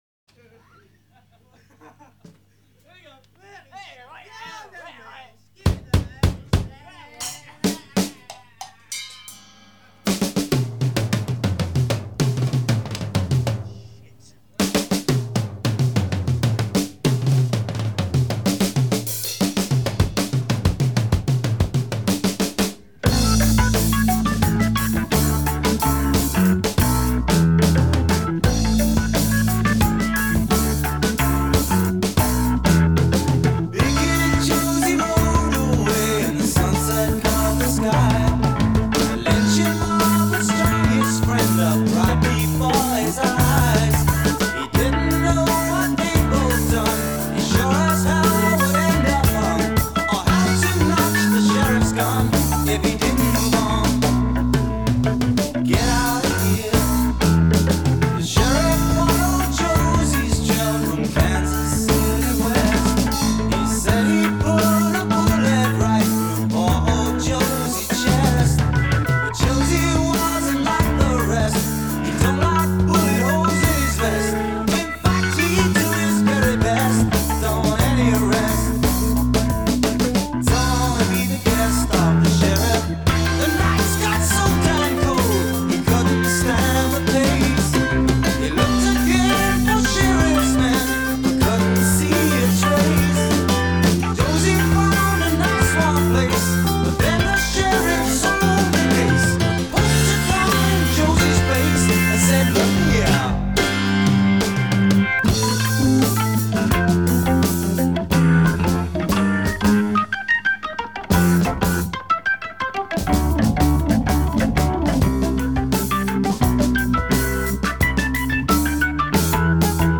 Psykedelisk Rock
der blander klassisk og rock i denne "country-ballade"